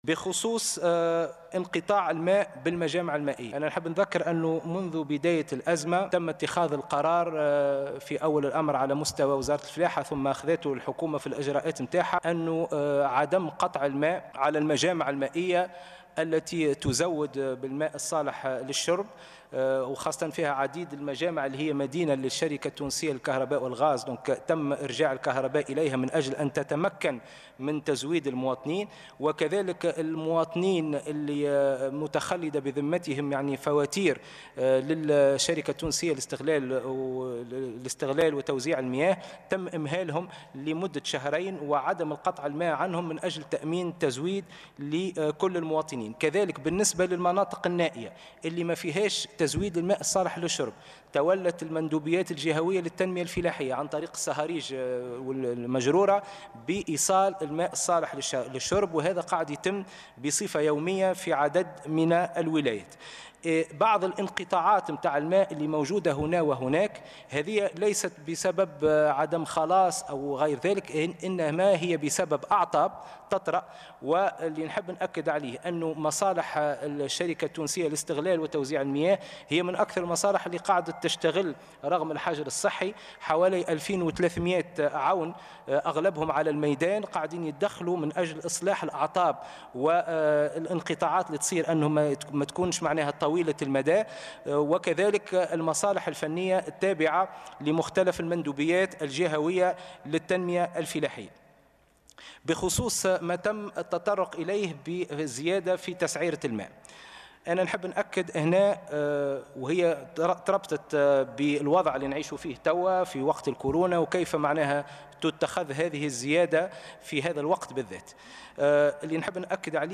أكد وزير الفلاحة والموارد المائية والصيد البحري أسامة الخريجي في مداخلة له خلال جلسة عامة لمجلس نواب الشعب، اليوم الثلاثاء أنّ الزيادة في تعريفة استهلاك مياه الشرب لن تشمل حوالي 5 ملايين شخصا و أنها ستغطي 74 بالمائة من كلفة المياه في تونس بعد أن كانت في حدود 65 بالمائة العام الماضي.